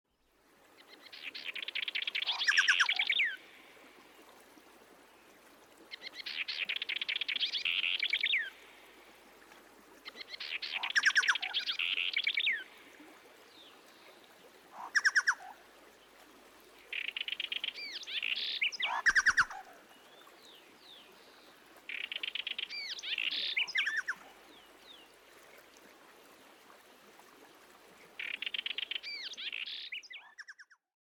Sonidos de Naturaleza
rio_avaes_refugio_del_alba.mp3